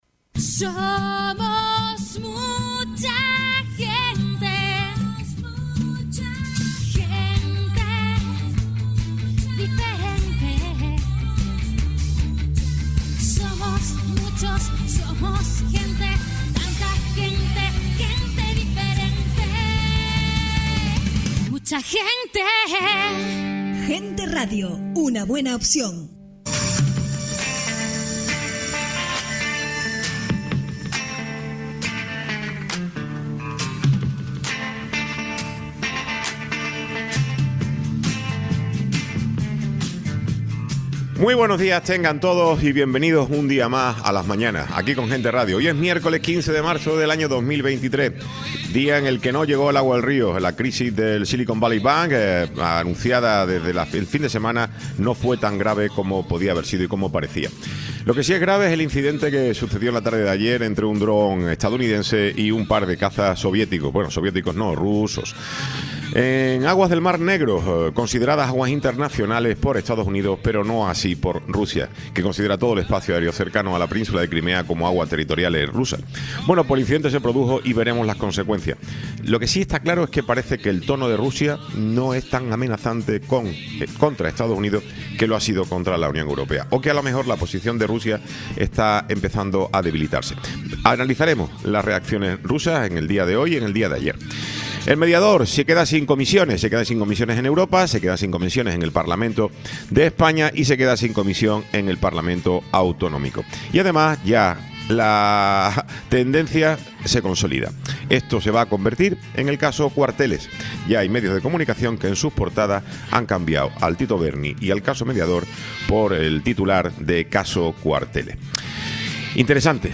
Tiempo de entrevista con Rosa Dávila, candidata CC a la presidencia del Cabildo de Tenerife Tiempo de entrevista con Verónica Meseguer, consejera CC en el Cabildo de Tenerife
Tertulia